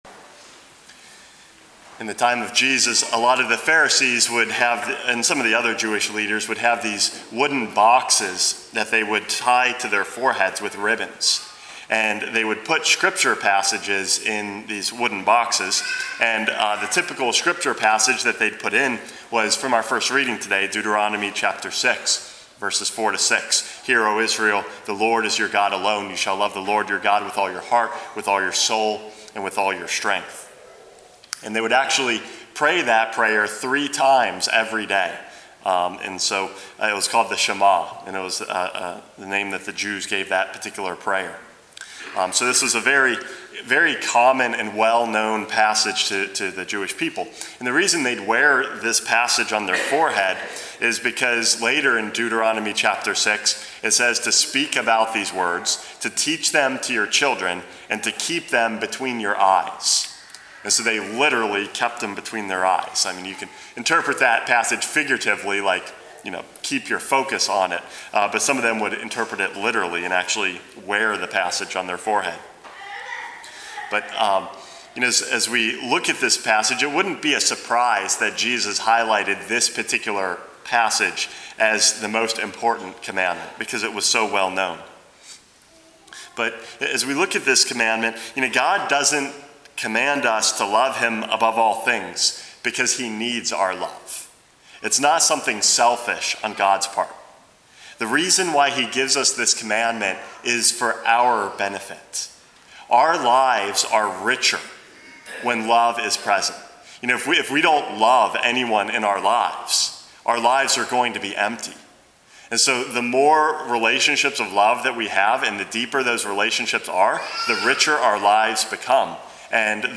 Homily #422 - To Love God